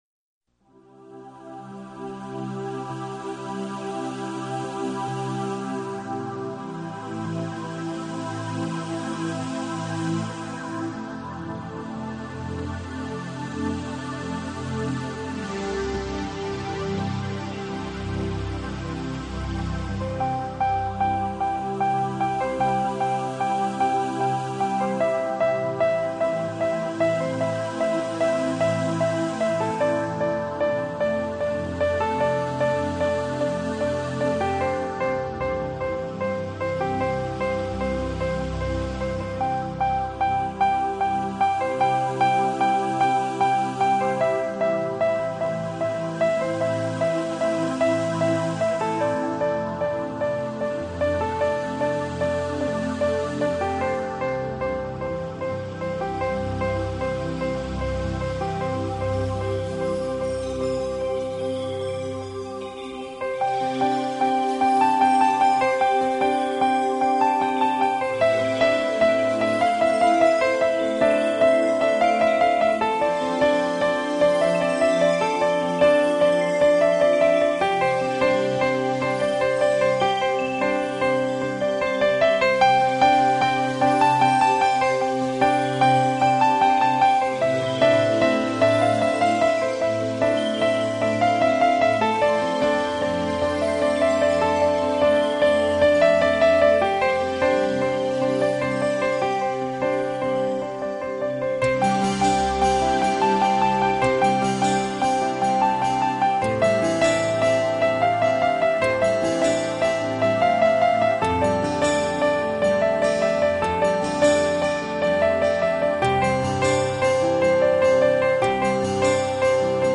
音樂類型： 演奏音樂  [休閒SPA音樂]
音樂成份：特別添加溫暖心跳的節奏、促進愛的循環的旋律、吸滿陽光歡悅的音符 。